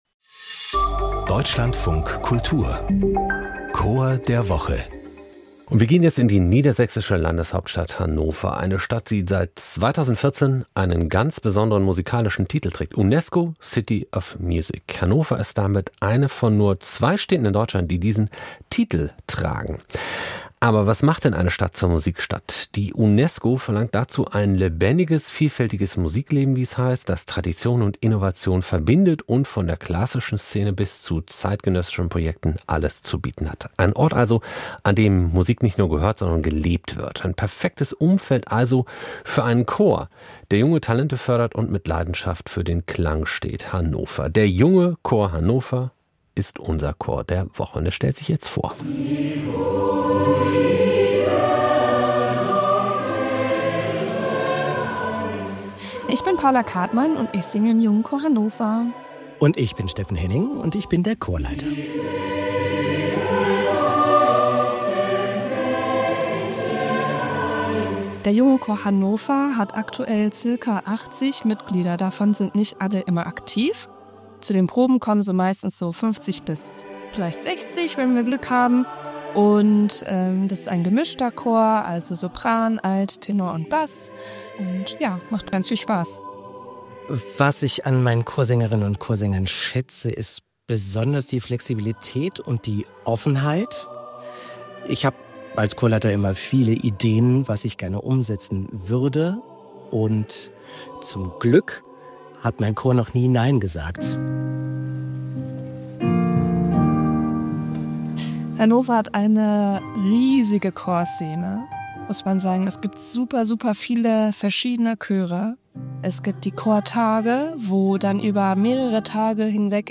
Hier stellen wir Ihnen jede Woche einen Chor vor.